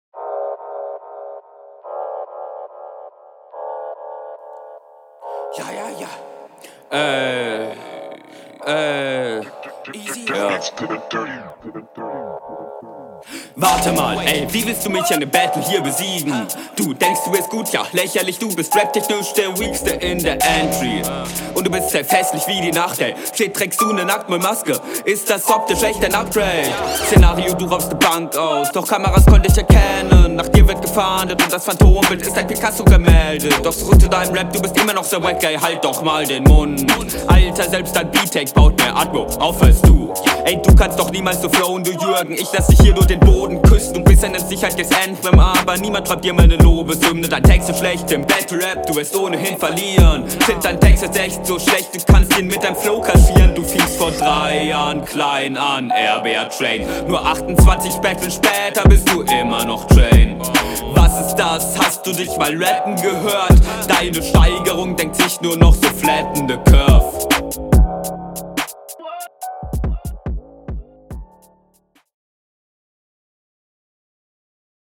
Wieder guter Sound.
Kranker Drill Beat, deine stärkste Runde in dem Battle.